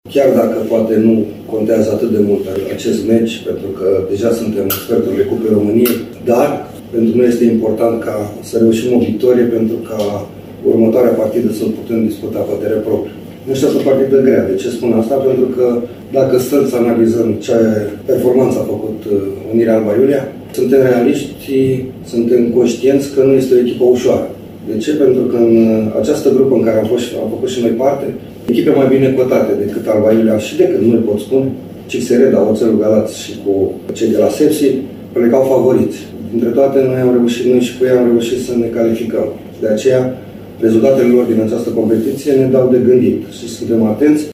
Tehnicianul Reşiţei subliniază faptul că deşi întâlneşte o formaţie de Liga a treia, echipa sa va avea, cu certitudine, o misiune dificilă în partida din Valea Domanului: